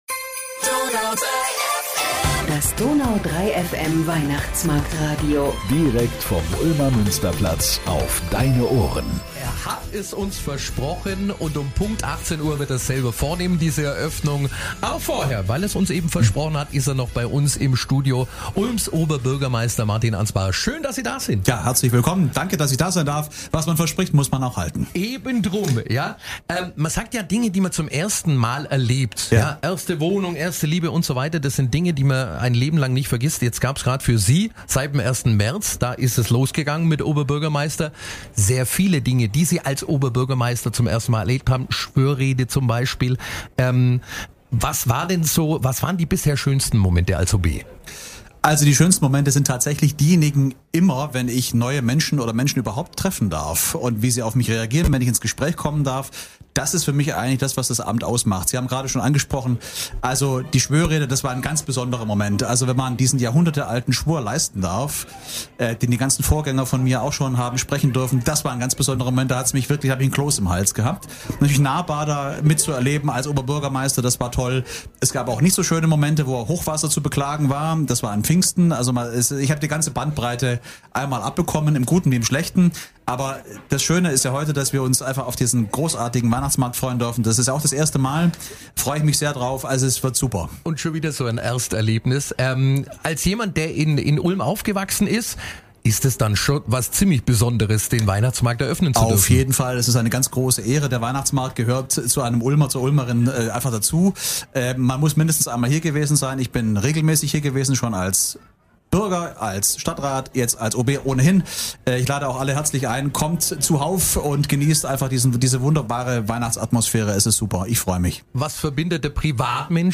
Direkt aus dem gläsernen Studio auf dem Ulmer Weihnachtsmarkt